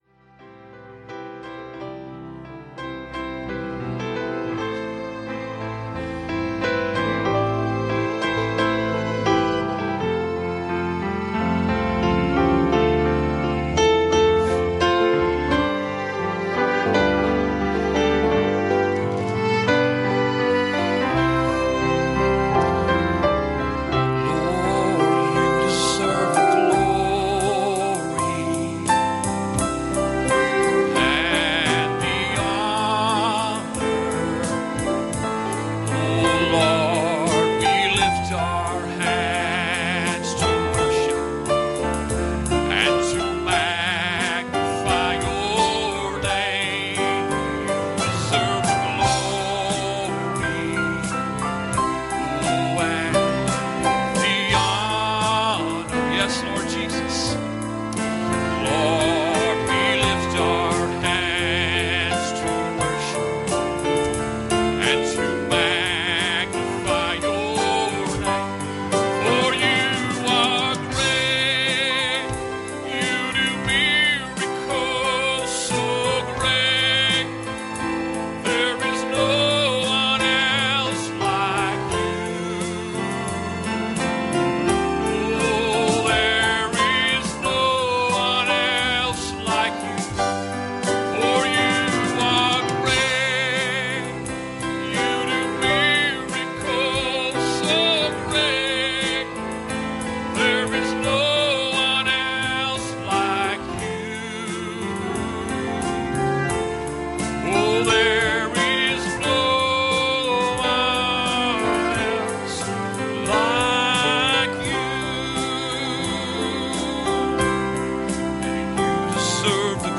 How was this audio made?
Numbers 13:31 Service Type: Sunday Morning "And Joshua and Caleb